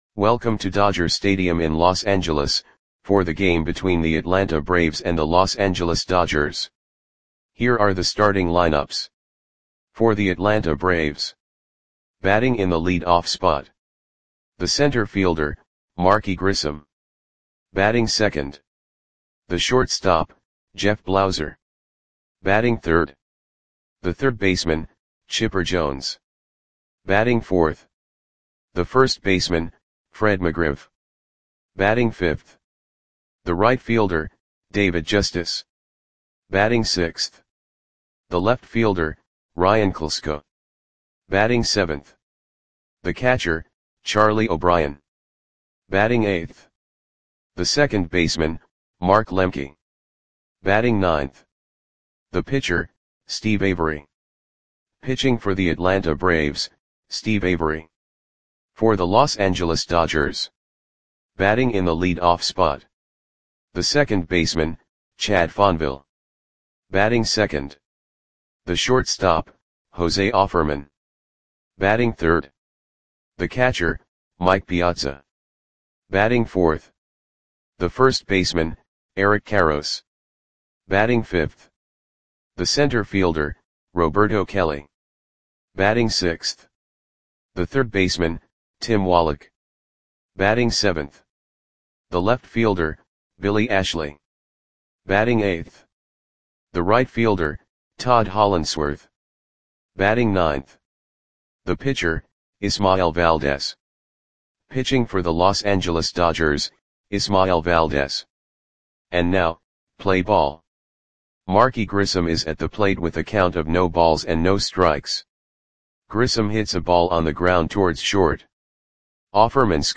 Audio Play-by-Play for Los Angeles Dodgers on July 26, 1995
Click the button below to listen to the audio play-by-play.